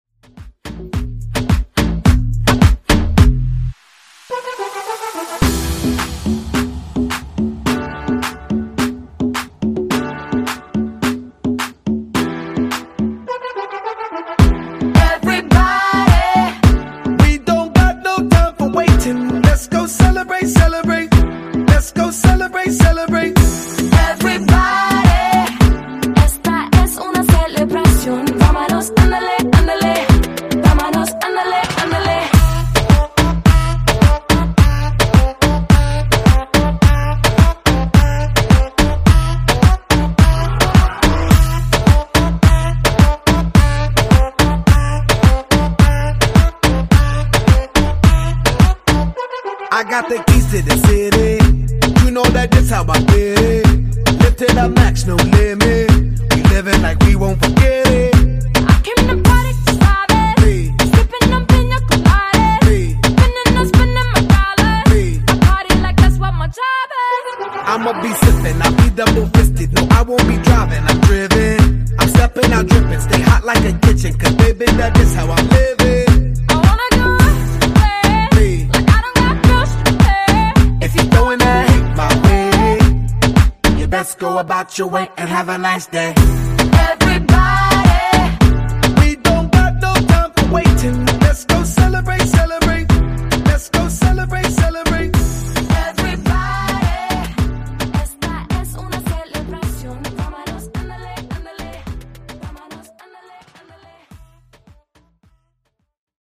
Genres: R & B , RE-DRUM
Clean BPM: 107 Time